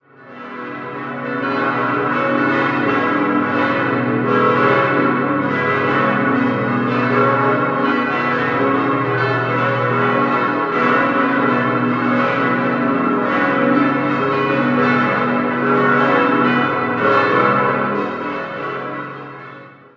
Sie verleiht dem Raum eine ganz eigene Atmosphäre. 7-stimmiges Geläute: gis°-h°-cis'-e'-fis'-gis'-h' Alle Glocken wurden 1959/60 von Friedrich Wilhelm Schilling in Heidelberg gegossen.